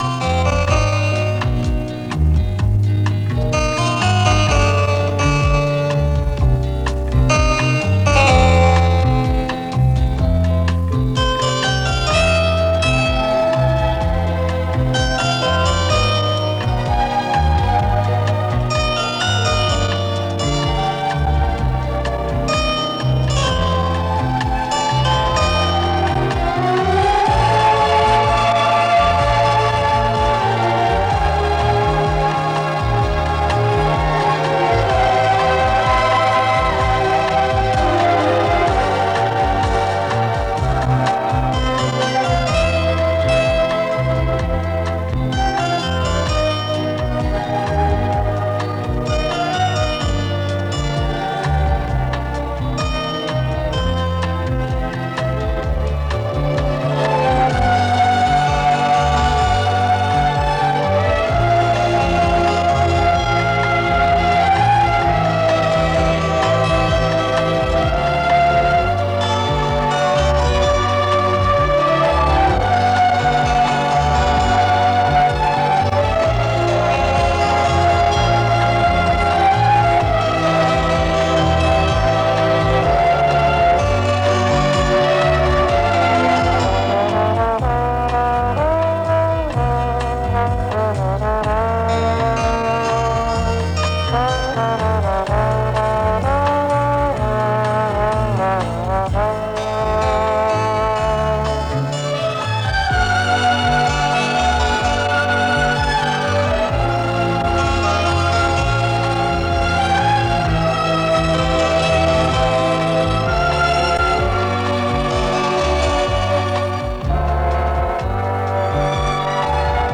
лирическая босса-нова
Из неизданных на пластинках записей, с плёнки радио